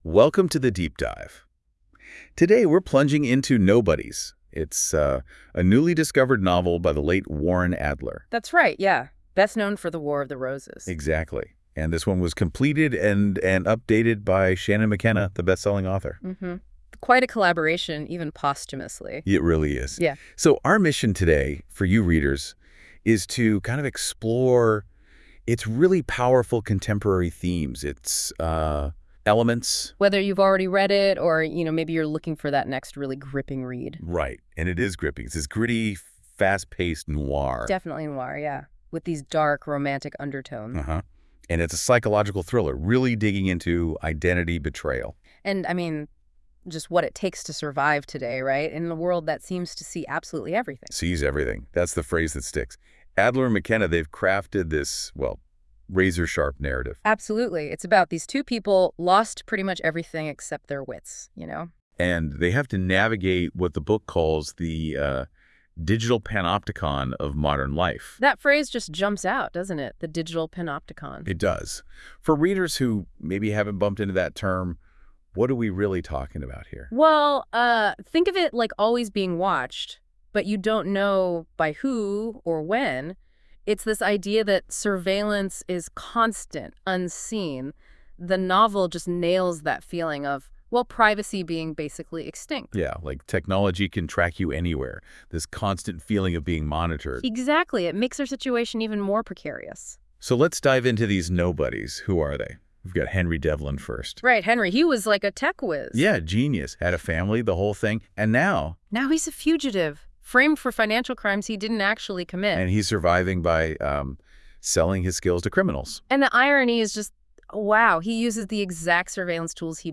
This episode: A lively and insightful conversation exploring the themes, characters, and deeper meanings within Warren Adler’s Nobodies